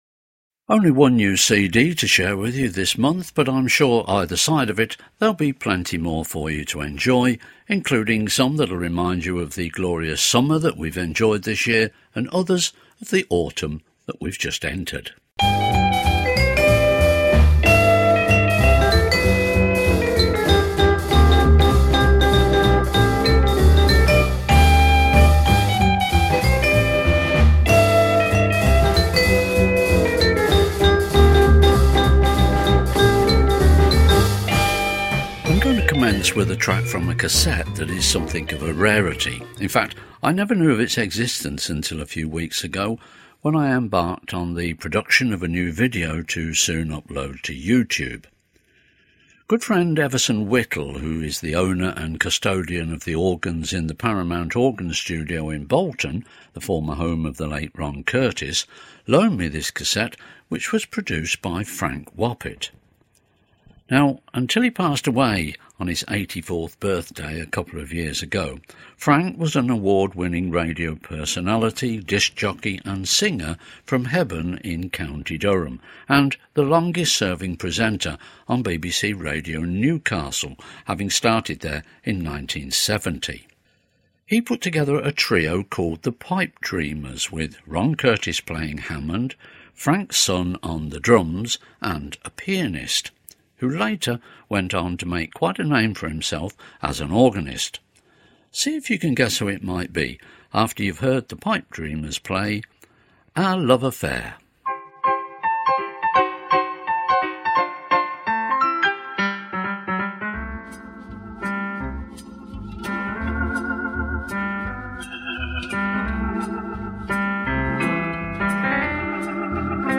is a monthly hour-long Podcast featuring electronic and theatre organ recordings.